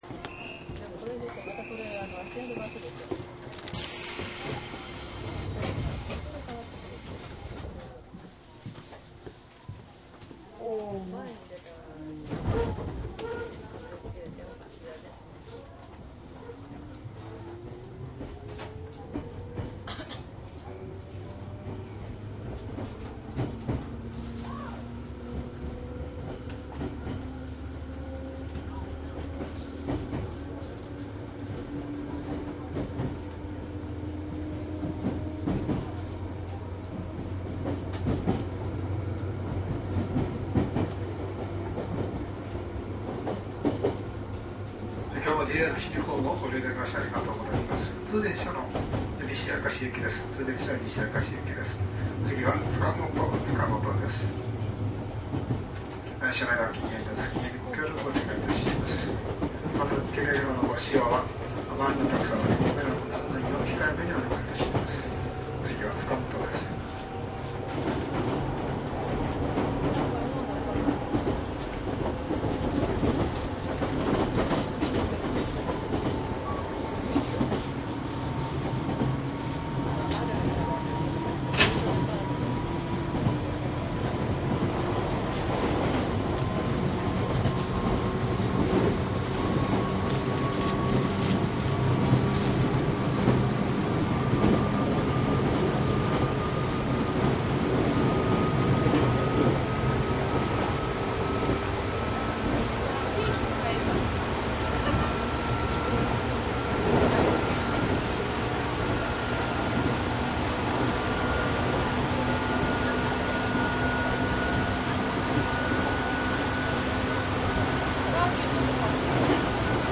商品説明♪JR神戸線 205系 鉄道走行音 ＣＤ ♪
内容はJR京都・神戸線205系  走行音  録音 ＣＤです。
205系は京都から西明石方面の電車で録音しています。205系は添加励磁制御 外扇形主電動機となります。
マスター音源はデジタル44.1kHz16ビット（マイクＥＣＭ959）で、これを編集ソフトでＣＤに焼いたものです。